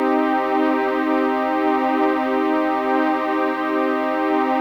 CHORDPAD 072
CHRDPAD072-LR.wav